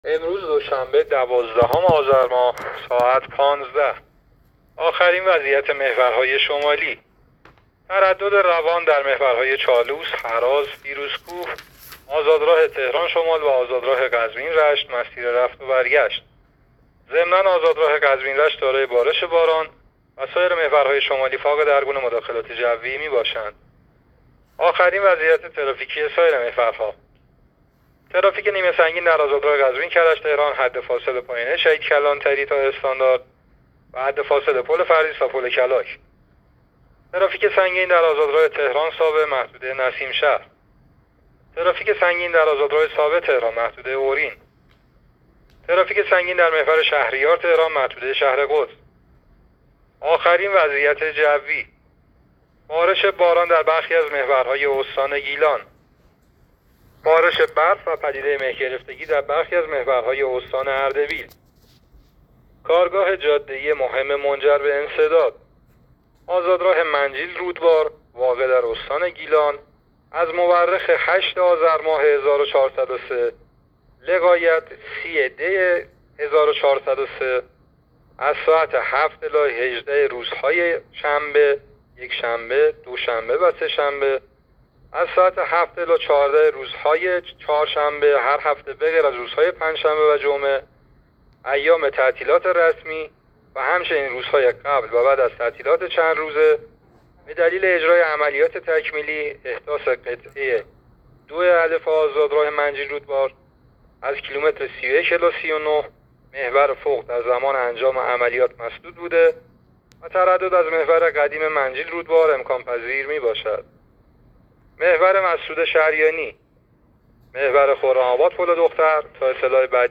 گزارش رادیو اینترنتی از آخرین وضعیت ترافیکی جاده‌ها تا ساعت ۱۵ دوازدهم آذر؛